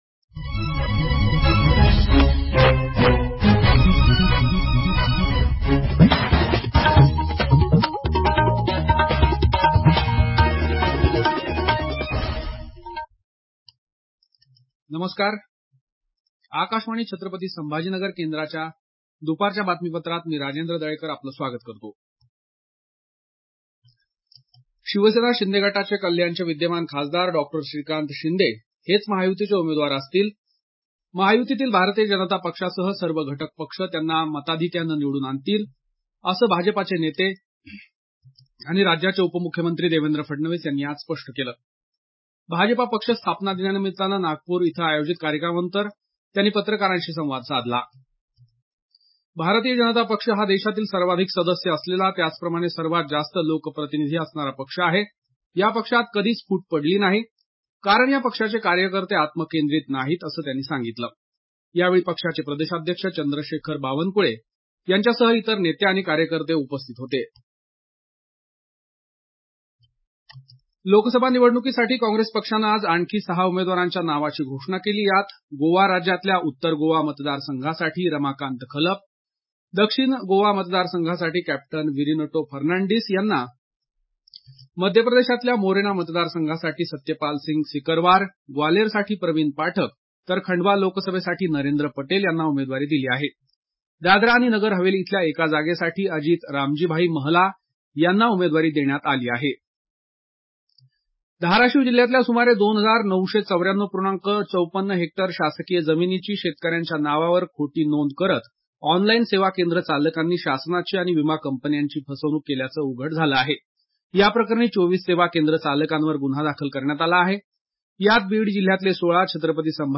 Transcript summary Play Audio Midday News